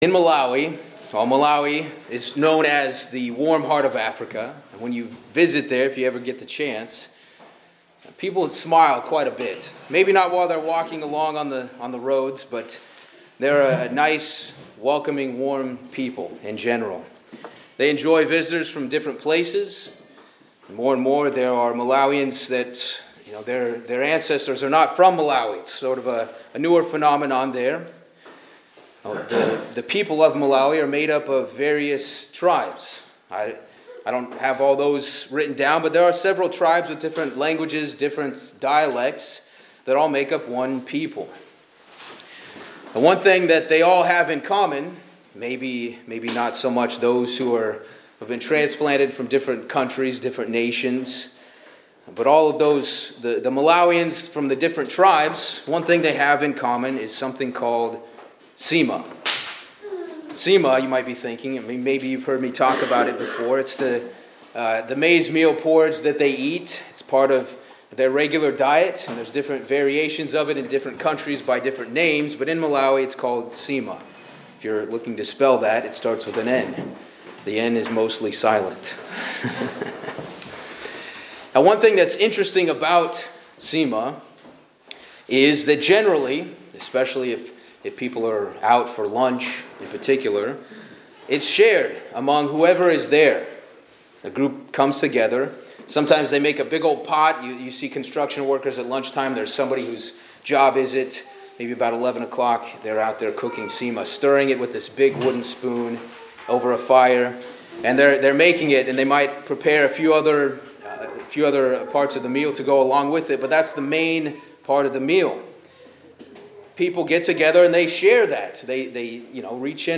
This message emphasizes the call for believers to strive for unity as one body in Christ, drawing on the shared symbolism of the Passover bread and the example of communal meals such as nsima in Malawi. Through passages in 1 Corinthians and Ephesians, the sermon explains that although God’s people come from different backgrounds, gifts, and experiences, they are joined together by one Spirit, one faith, and one sacrifice, Jesus Christ. The message calls for personal and collective renewal, putting off the old self, embracing righteousness, and actively working toward peace and unity within the Church of God.